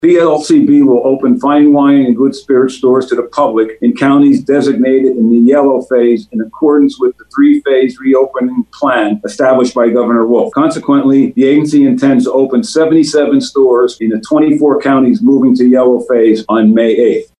Tim Holden, PA Liquor Control Board Chairman, talked about the re-opening.